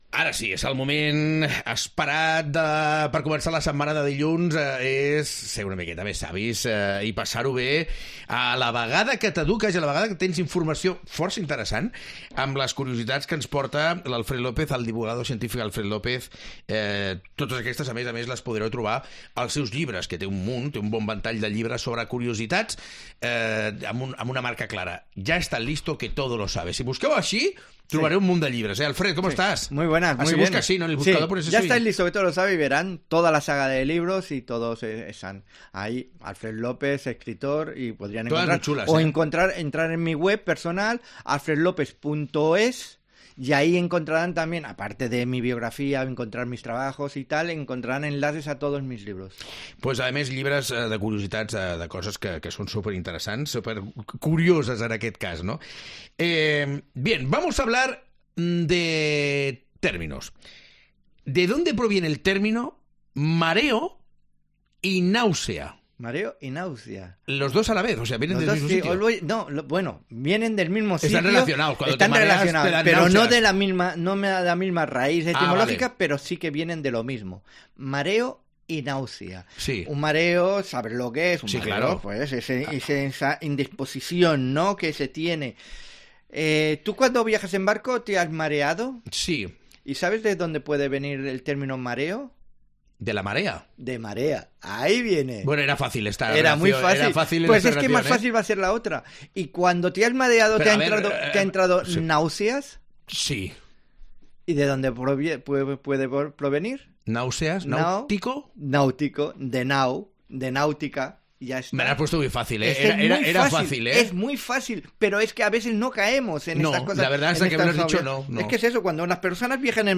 divulgador científico